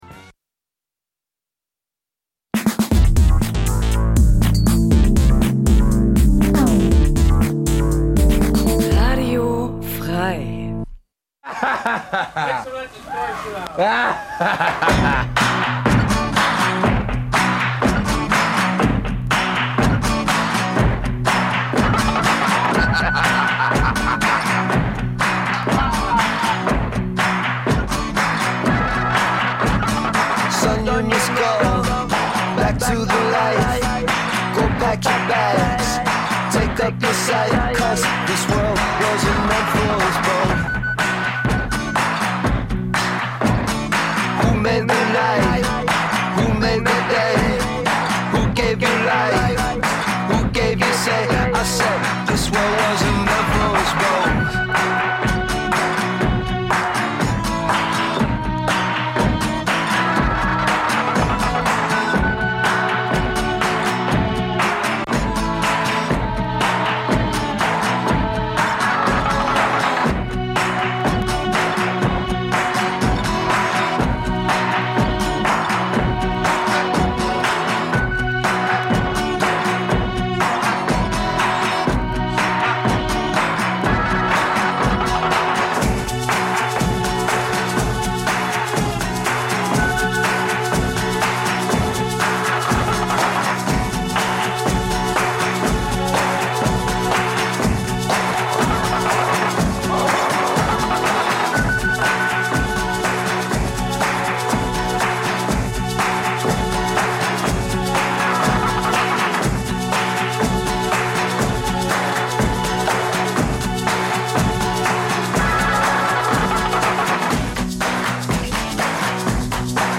Zwei Musikfreunde duellieren sich mit raren Rock- und Punklegenden... - vinyl meets mp3... crossover zwischen den Welten, Urlaubsmusik und Undergroundperlen. Abgr�nde Ihres Musikgeschmacks tun sich scheinbar unkontrolliert auf.